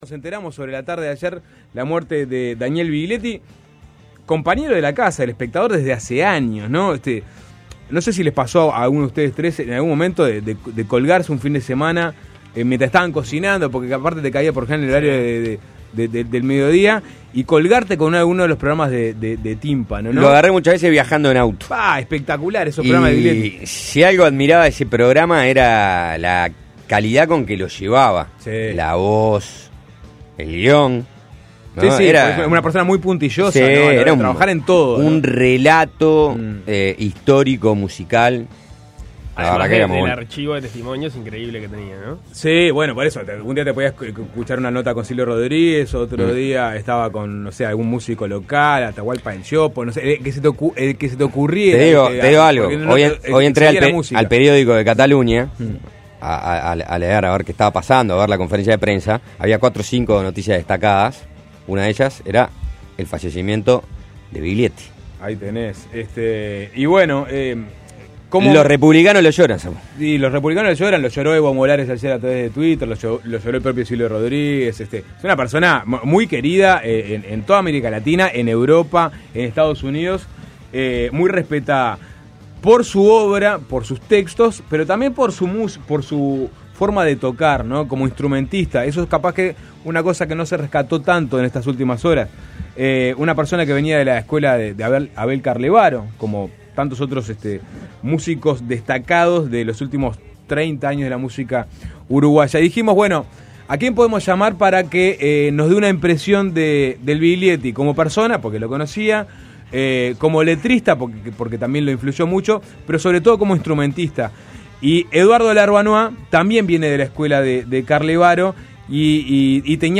En entrevista con Suena Tremendo, Larbanois expresó "me impresionó su disco Canciones para el hombre nuevo (1968).